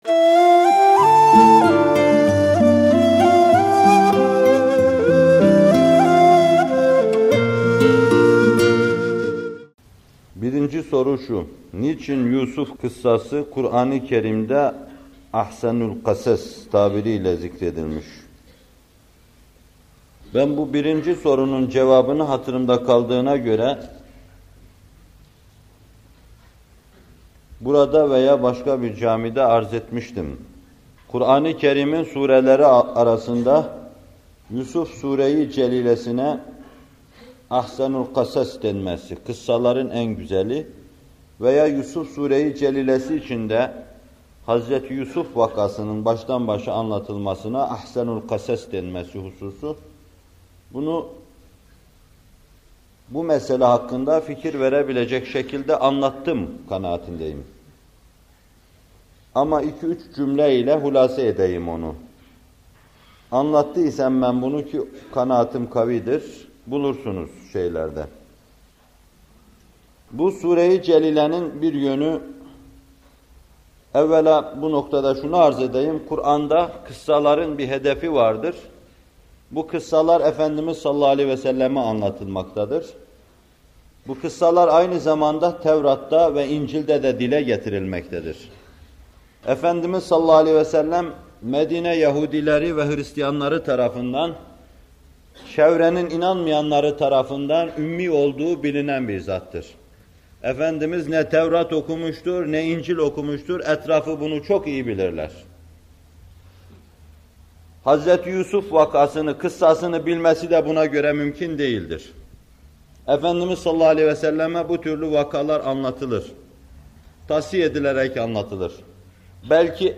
Muhterem Fethullah Gülen Hocaefendi bu videoda *Yusuf Suresi 3. ayet-i kerimesinin* tefsirini yapıyor: